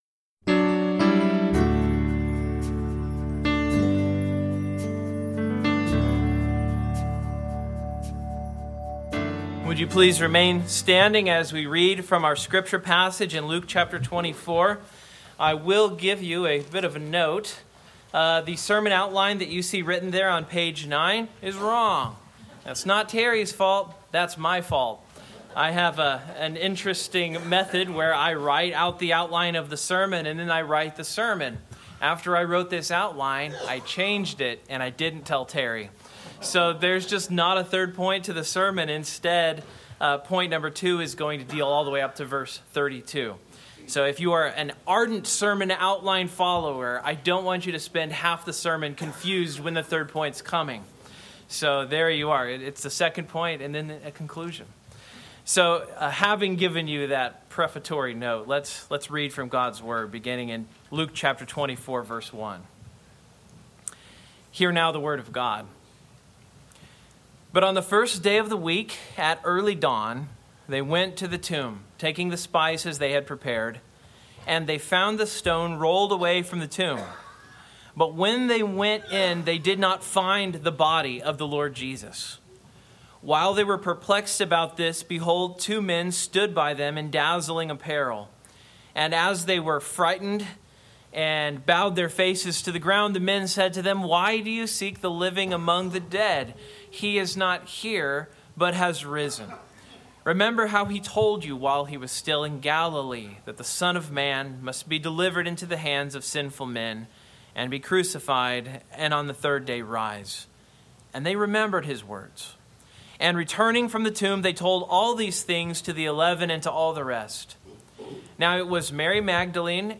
Sermon Outline Main Point: The risen Lord Jesus drives us to the Scriptures to confirm that he was raised up.